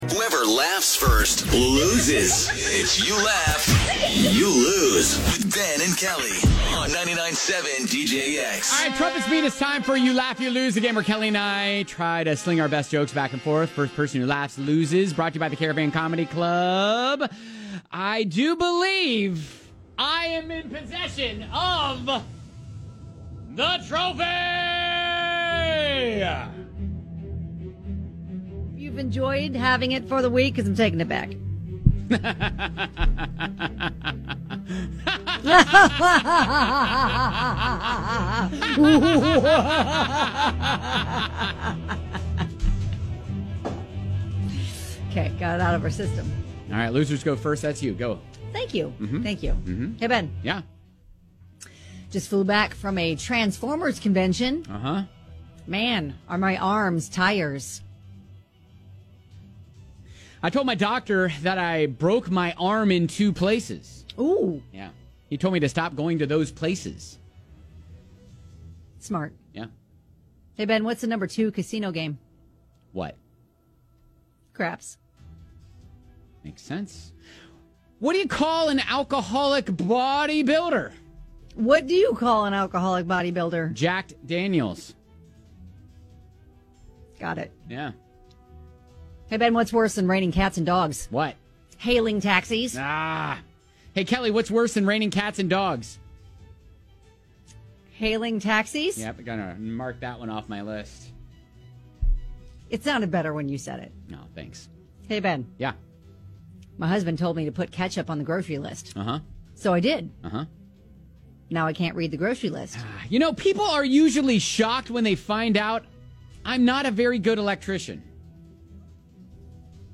toss jokes back and forth until someone laughs!